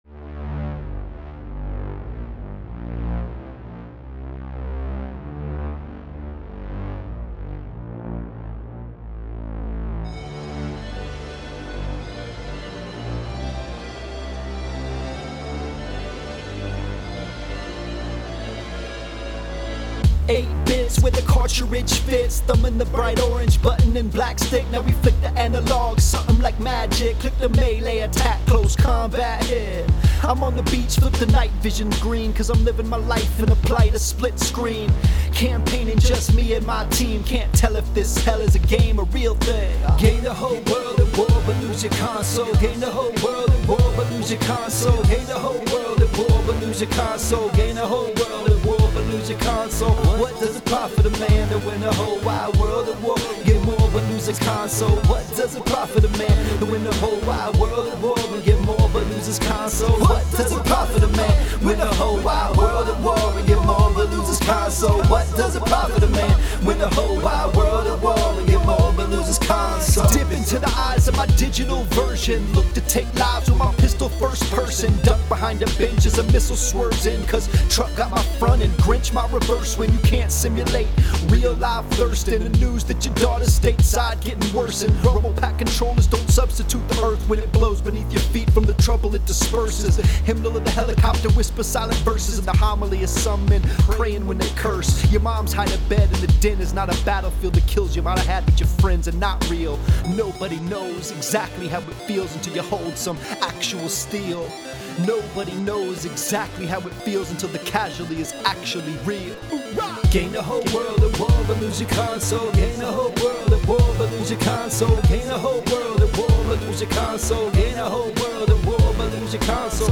That’s what happens in the Middle East and Africa where children grow up with berettas in their hands (maybe I mean AKs; sheesk, I don’t know, I’m not a gun trafficker, just a news rapper).